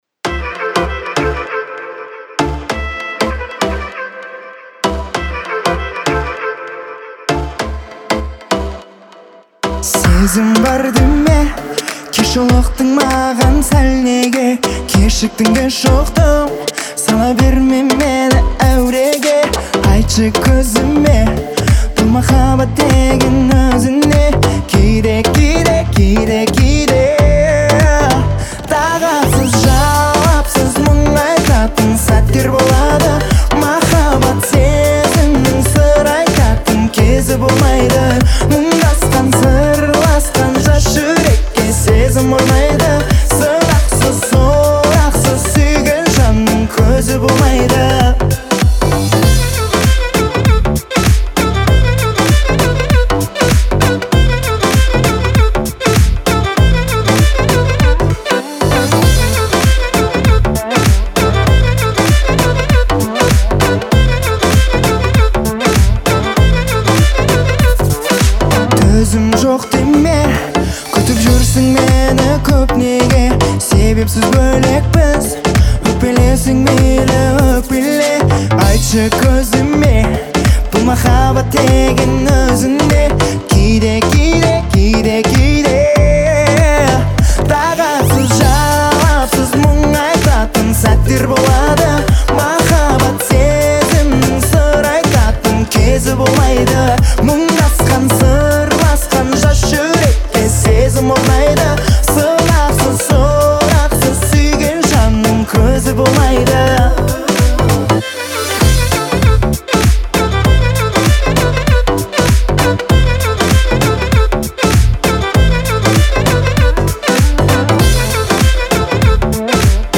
это яркий пример казахского поп-музыки